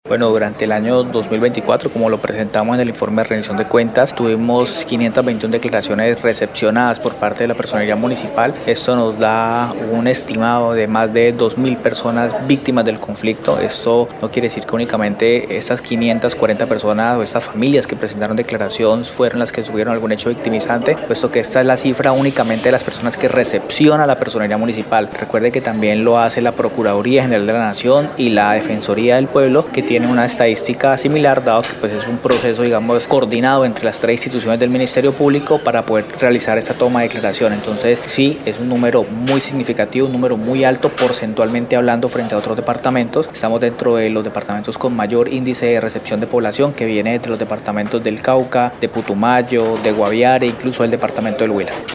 Jorge Luis Lara Andrade, personero del municipio de Florencia, explicó que estas familias, unas dos mil personas, llegaron desplazados a la capital huyendo de las extorsiones y los homicidios de firmantes de paz y líderes sociales.